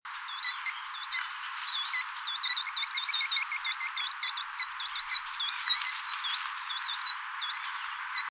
29-1石山2012mar25灰頭花翼alarm1.WAV
紋喉雀鶥 Alcippe cinereiceps formosana
南投縣 信義鄉 石山
錄音環境 路邊灌木叢
鳥叫
錄音: 廠牌 Denon Portable IC Recorder 型號 DN-F20R 收音: 廠牌 Sennheiser 型號 ME 67